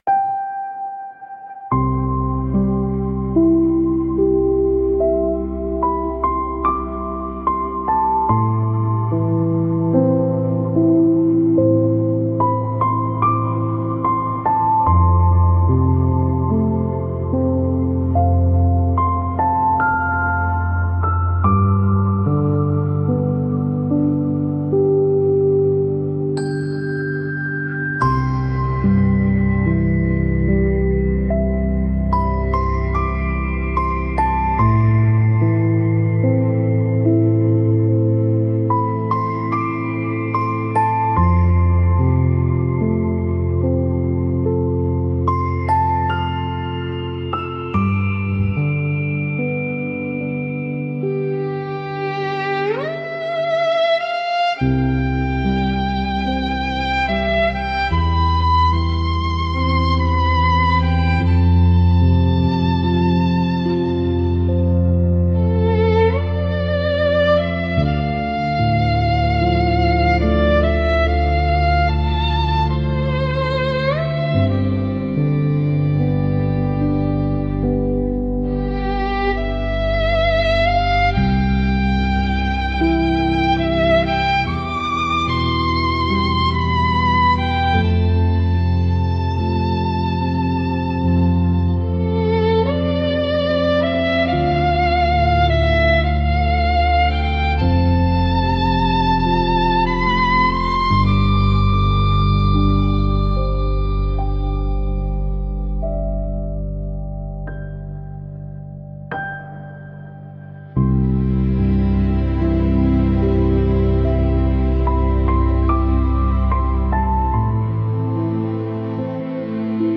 【助眠音乐】无声雪原永恒 - AI音乐
静谧降雪氛围，超慢29 BPM节奏，7000Hz+高频率水晶声，微风白噪音几乎听不见，动态范围低至-50dB，无元素干扰，完美实现全身心放松与深度睡眠，婴儿般轻柔入眠，无累积低动态，无缝平缓过渡
silent snowfall sleep ambient, ultra slow 29 BPM, high crystals 7000Hz+, white noise breeze barely audible, dynamic to -50dB